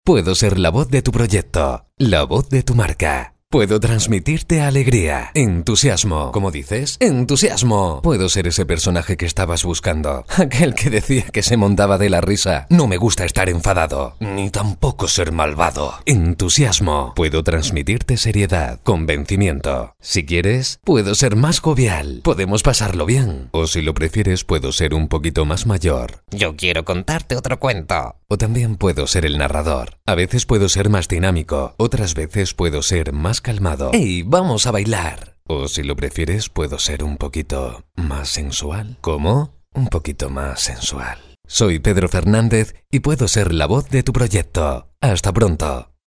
Voice persuasive, convincing, multiple records.
kastilisch
Sprechprobe: eLearning (Muttersprache):